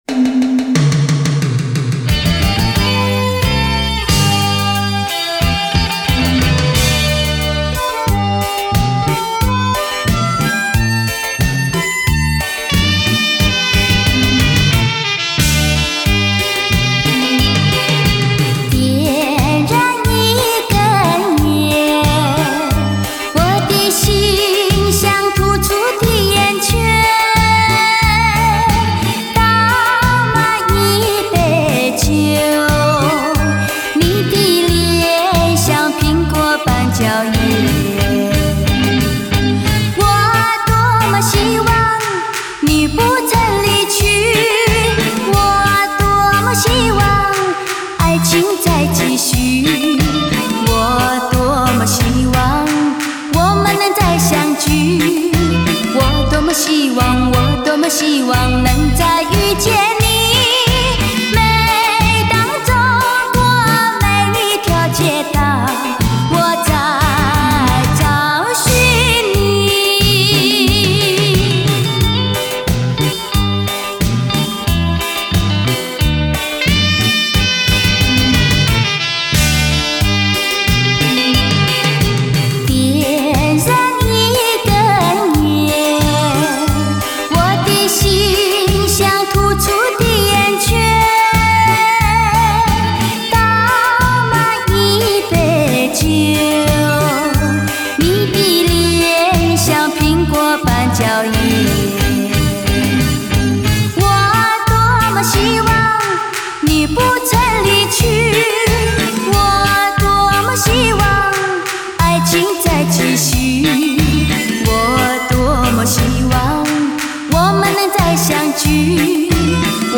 如痴似醉的歌声以细腻轻婉见长 典雅华丽的娓娓软语感染力极强
甜美轻盈的如醉歌声，犹如细腻轻婉的音乐之境，慰以心灵不尽柔美的天籁享受！